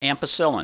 Pronunciation
(am pi SIL in)